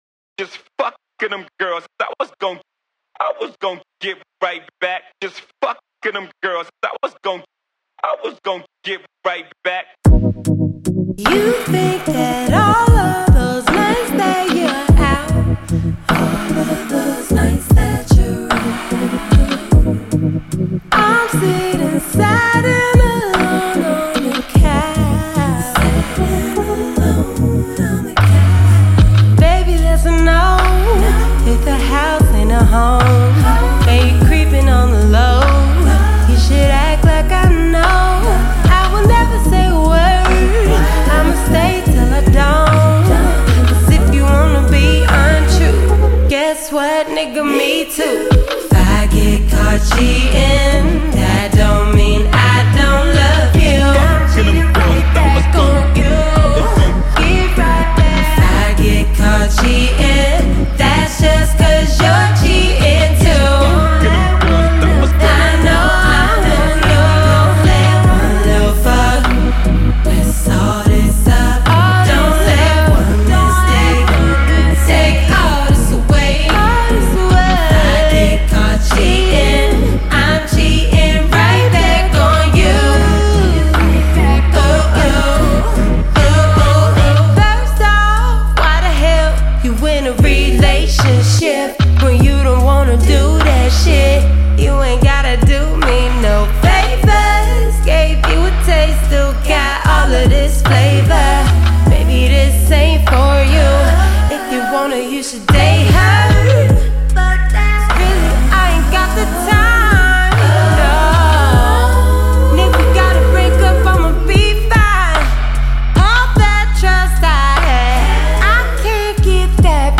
Genre: R&B/Soul.